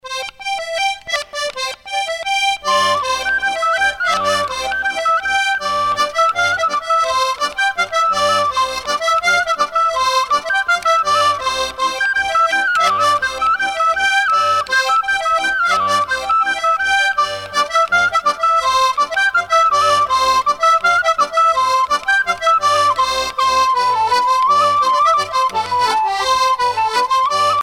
danse : branle : courante, maraîchine
Pièce musicale éditée